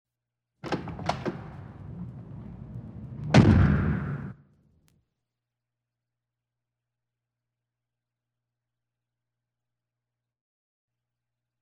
100 Sound Effects Vol 1 - 69 - Door Slamming - Madacy Music Group Inc.